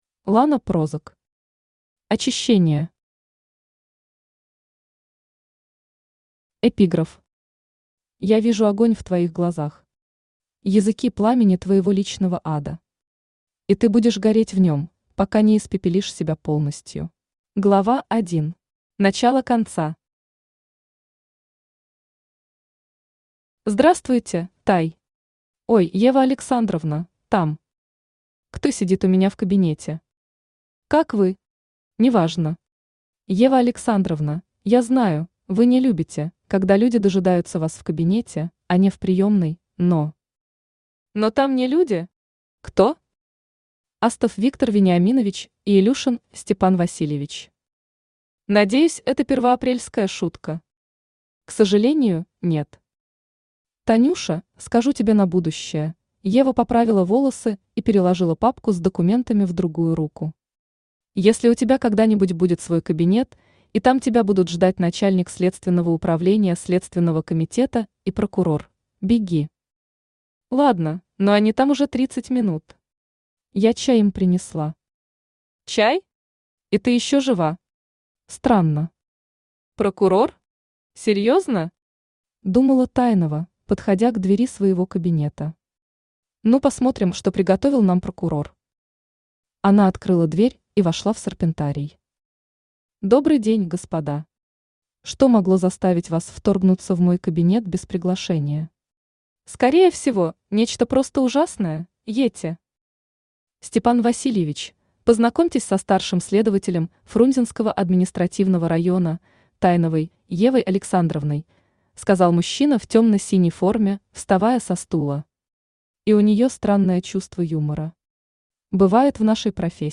Аудиокнига Очищение | Библиотека аудиокниг
Aудиокнига Очищение Автор Лана Прозак Читает аудиокнигу Авточтец ЛитРес.